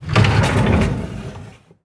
drawer_open.wav